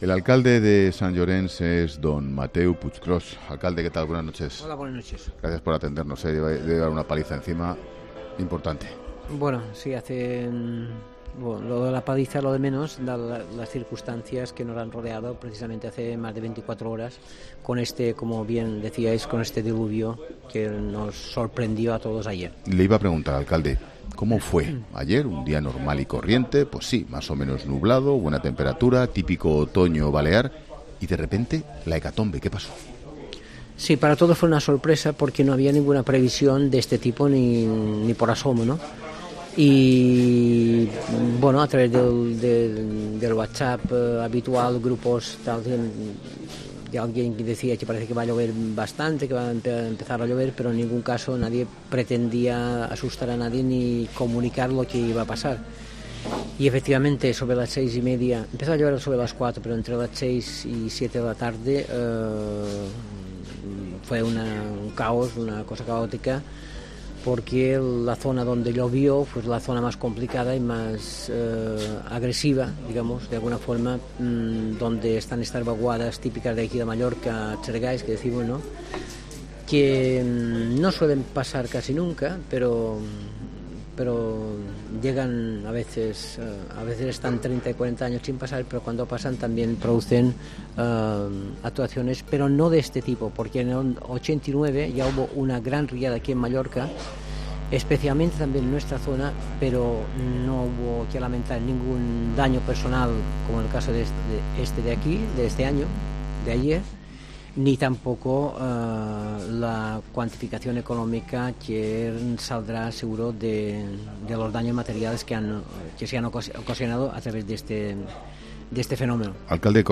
El alcalde de Sant Llorençs, Mateu Puigròs, en 'La Linterna'